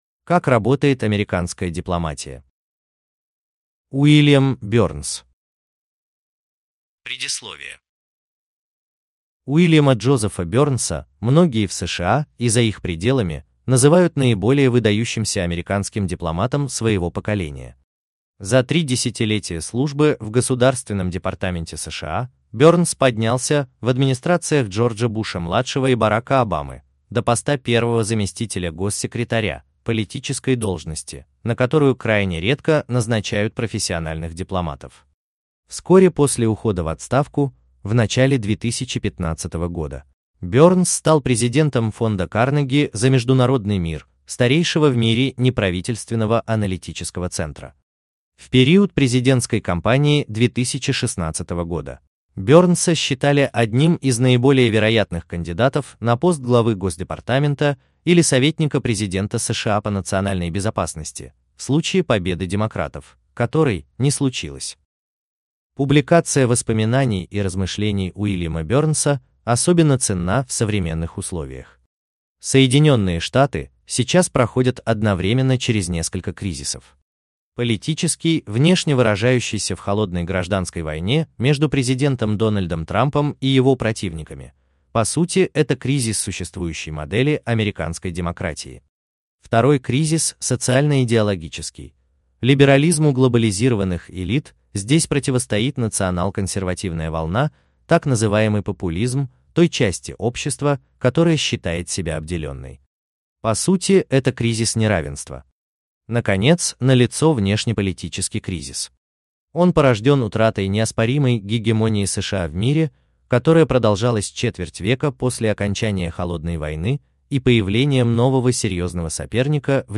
Аудиокнига Невидимая сила | Библиотека аудиокниг